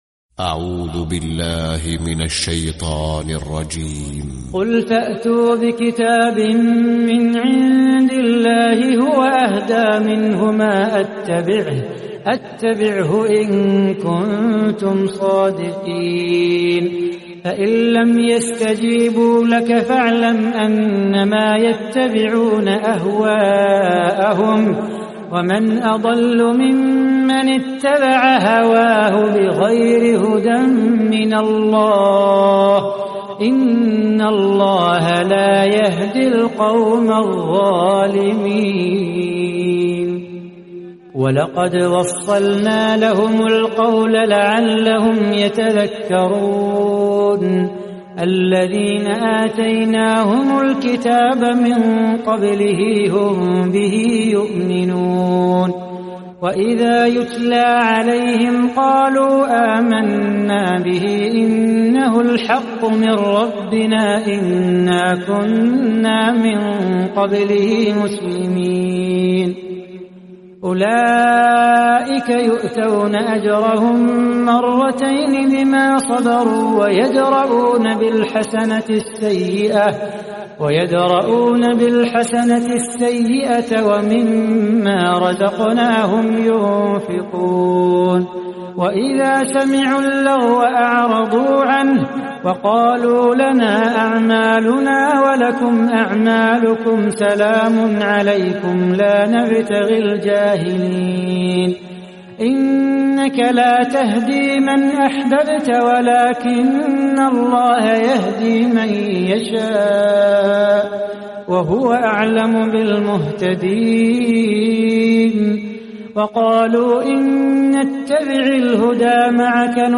🌾💛•تلاوة مميزة•💛🌾
👤القارئ : صلاح بو خاطر